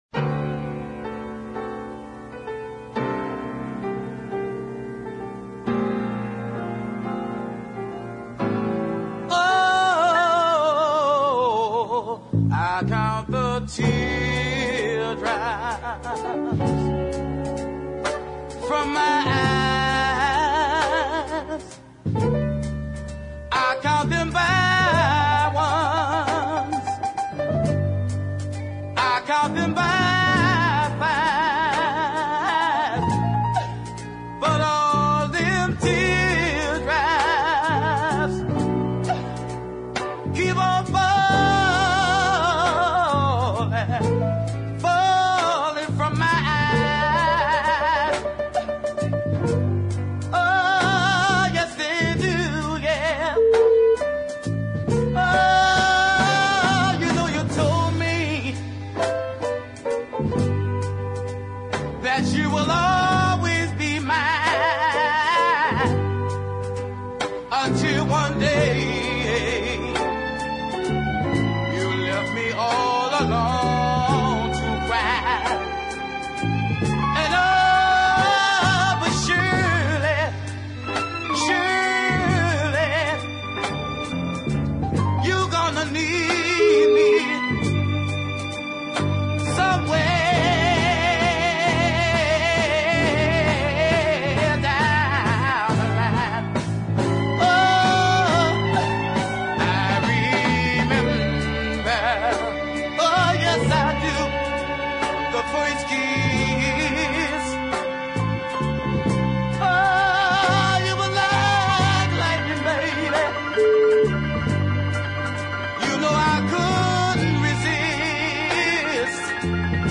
ballad